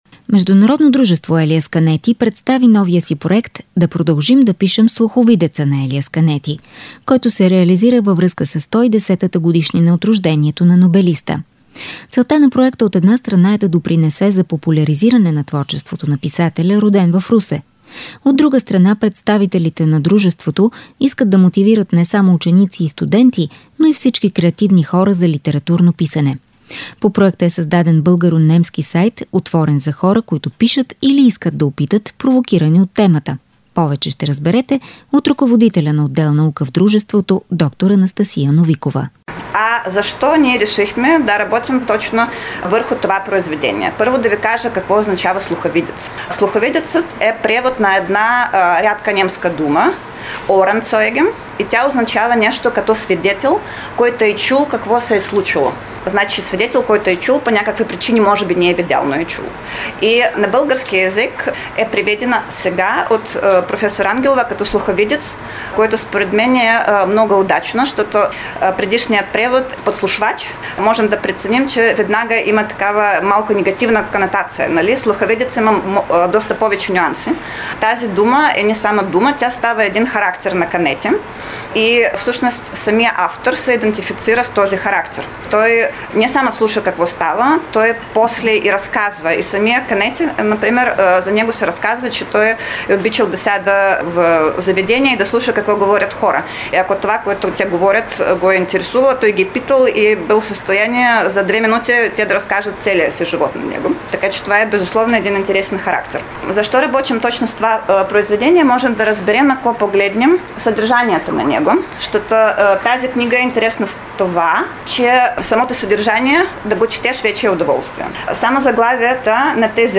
репортажа